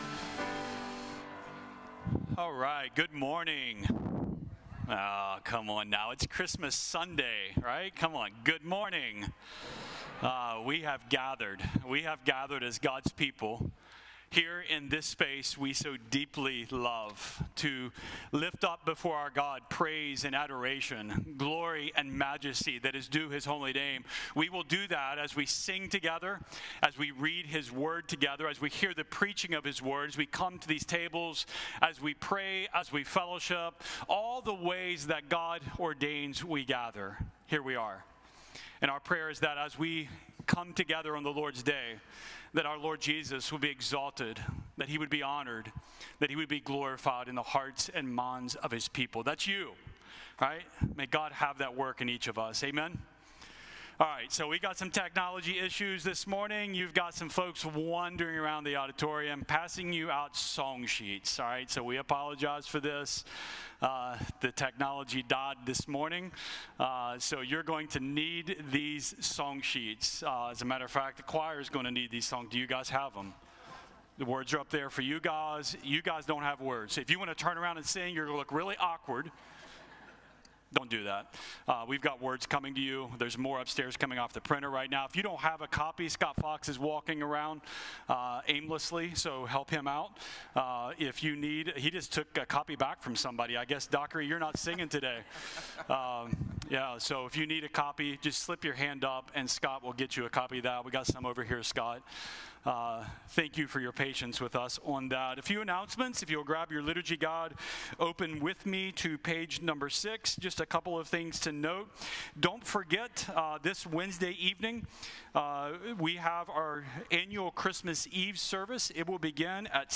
Sermons preached outside of normal exegetical sermon series, many times by guest speakers.